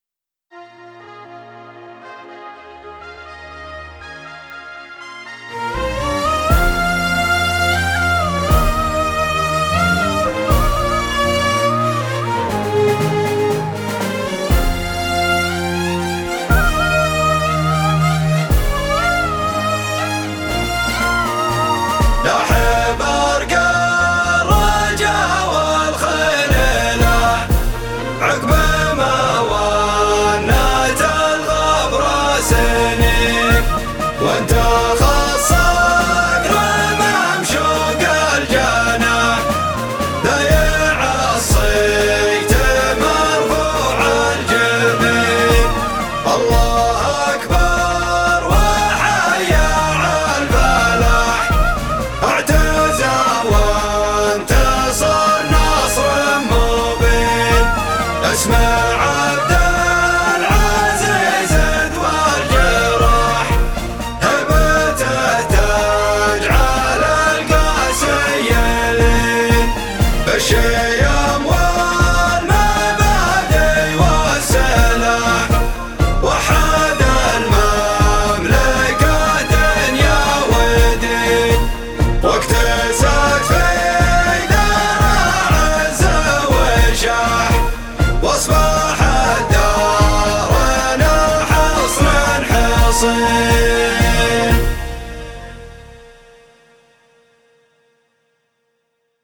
زامل. اوبريت اليوم الوطني – في محافظه تثليث